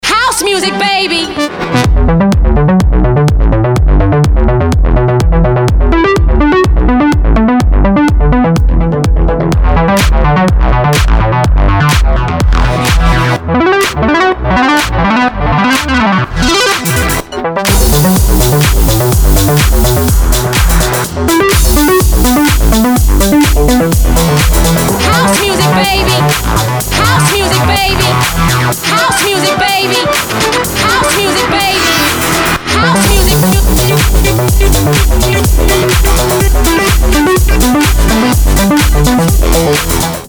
• Качество: 320, Stereo
club